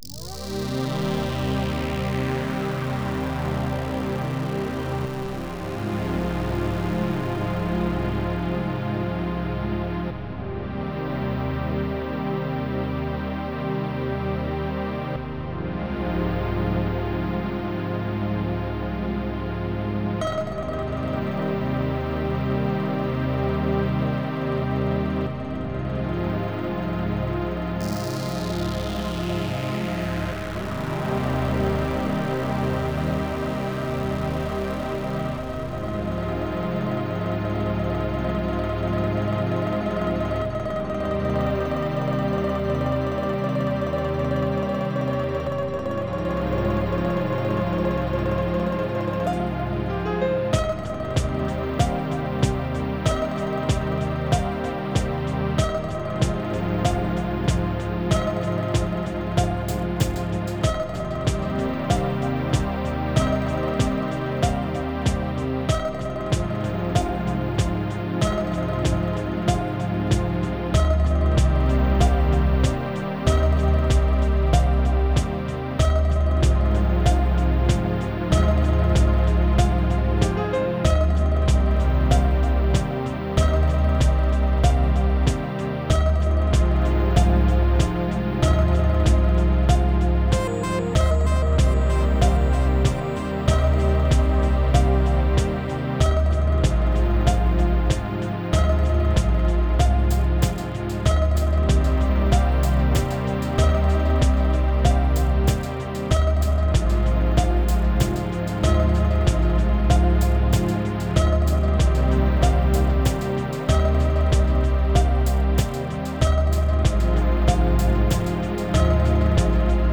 Description: A downtempo chill out track that I wrote and produced. 95 BPM, key of C minor.